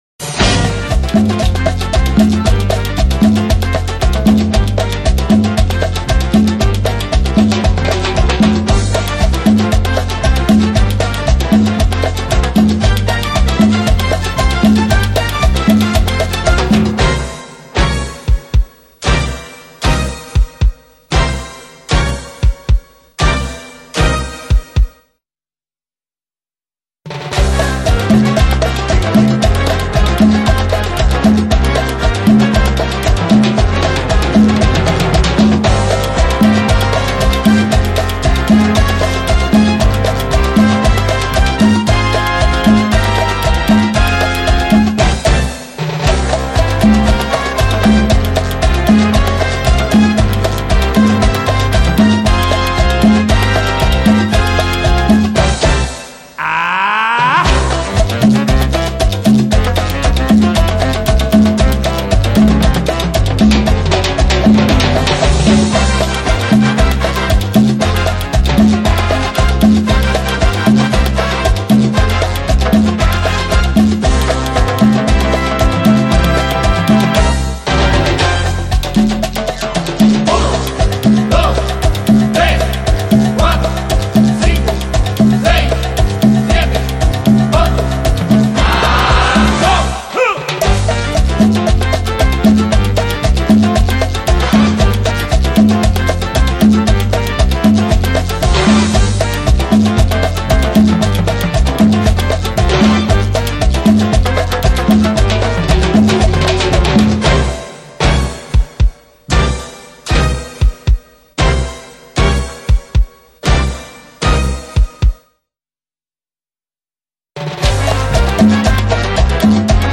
New Age
门金曲，旋律性强而富有动感，散发着无穷的时尚气息。